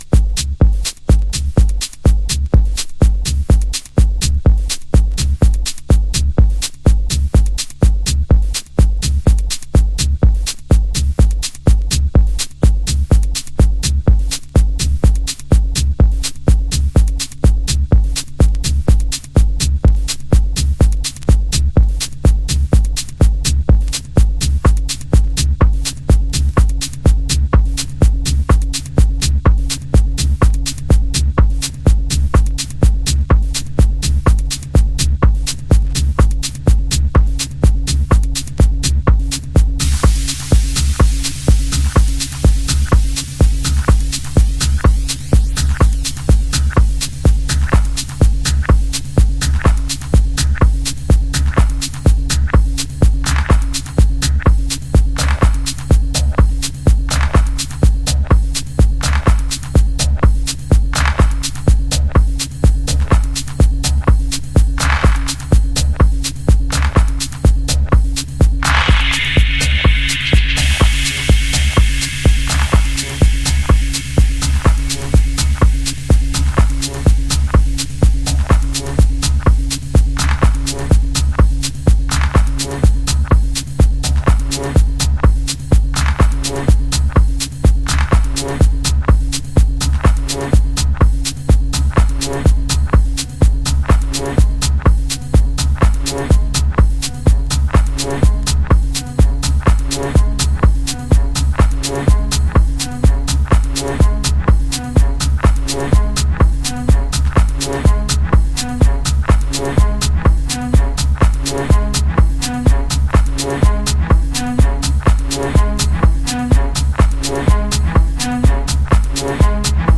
House / Techno
疾走する硬質なビートの背後で蠢く不穏なストリングスが印象的なディープテクノ。